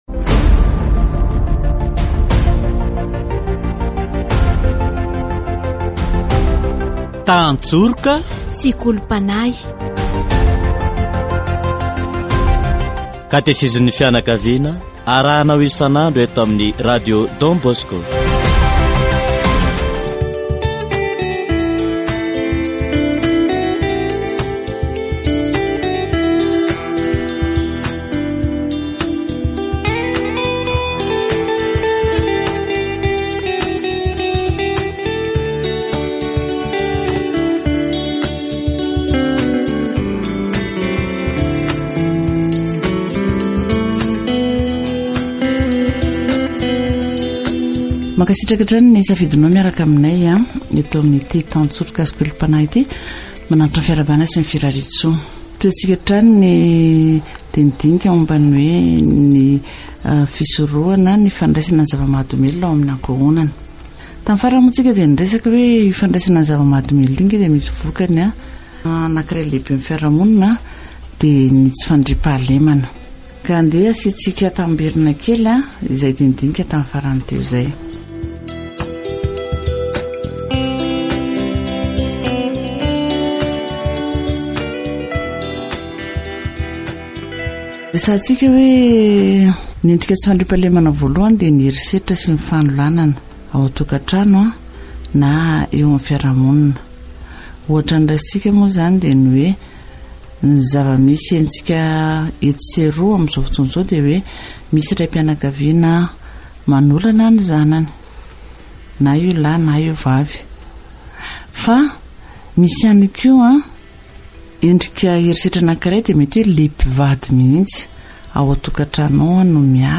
Katesizy momba ny fandraisana zava-mahadomelina